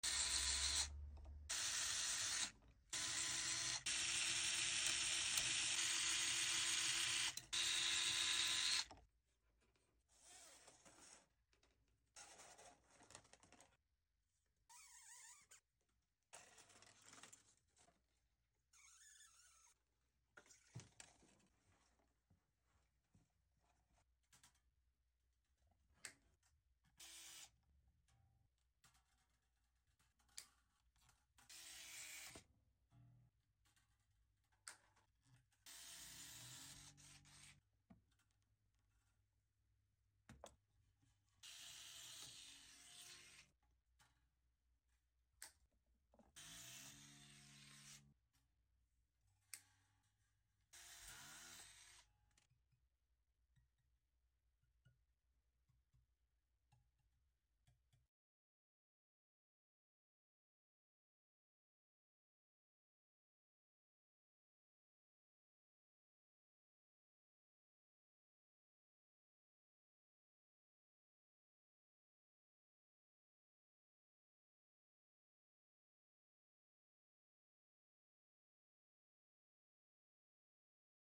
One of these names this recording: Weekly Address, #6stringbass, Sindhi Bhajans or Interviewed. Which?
#6stringbass